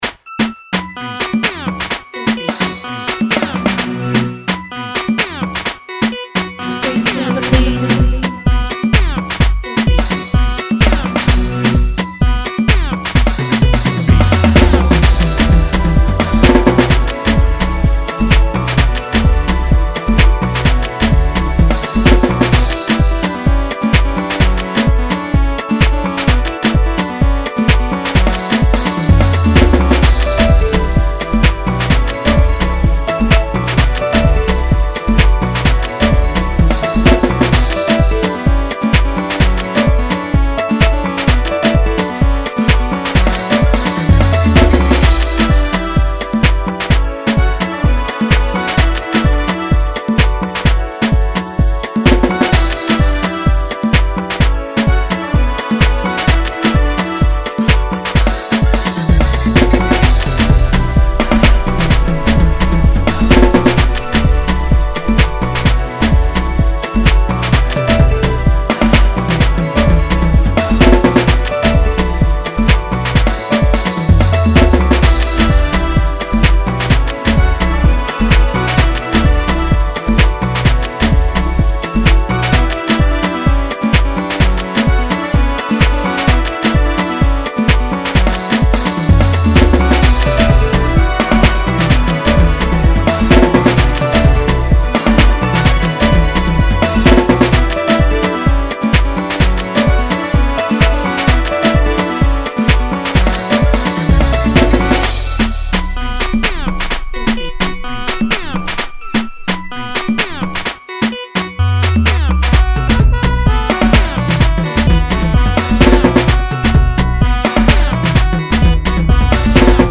Afro fugi beat